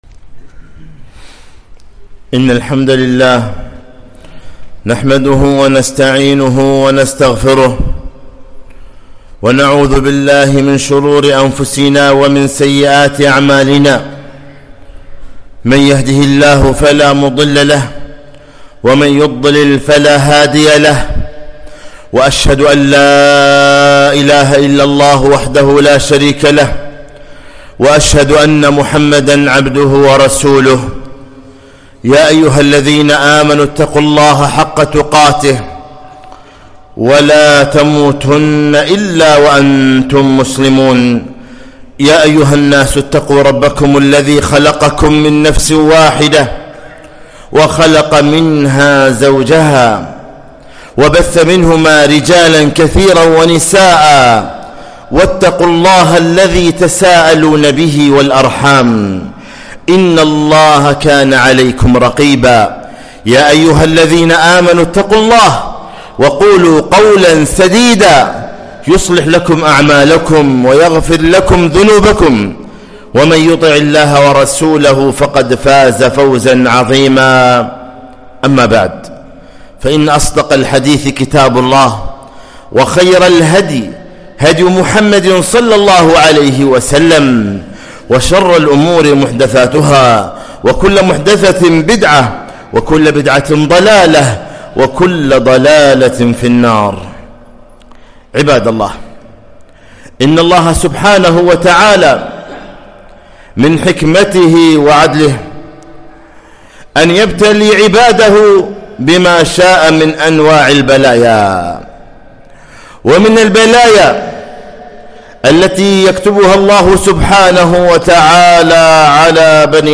خطبة - (رسالة إلى كل مريض )